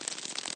beetle_wings_short.ogg